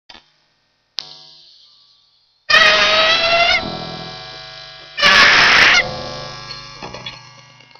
hum, on a essayer, ça a donner un truc, lui avec ça guitare il a un son, moi avec la basse un son aussi, avec une reverbe, beaucoup de tonaliter et un micro simple ... ( j'avais pas oser le dire avant )
des interets a faire ça ? tres peu, mais avec un peu d'imagination ça peut faire un truc ... mais on a pas vraiment chanter ... ça ressemble a une mouettes qui agonise ... mais c'est marrant
et là a travers la basse
mouette.wma